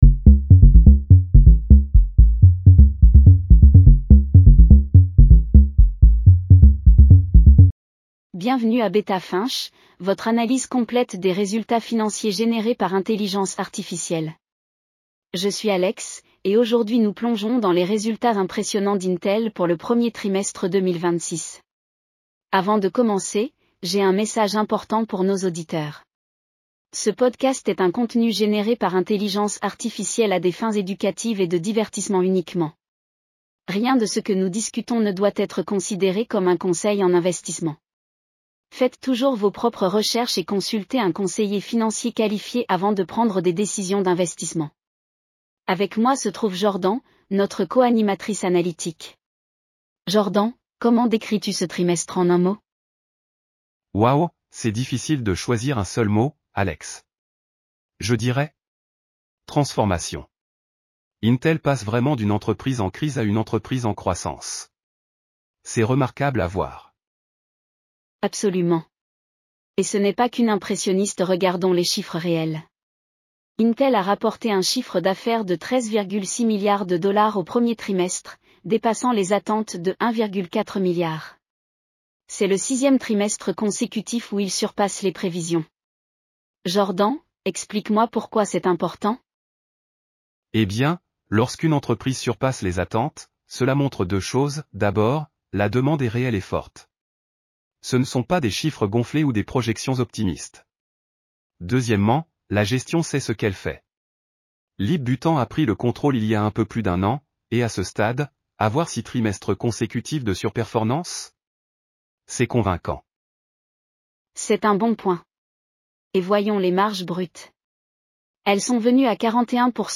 BETA FINCH PODCAST SCRIPT